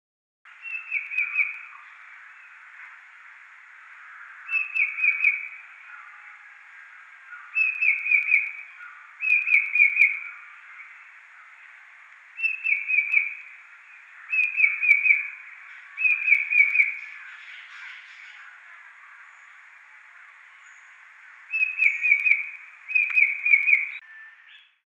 Banded Bay Cuckoo – song
Banded-Bay-Cuckoo.mp3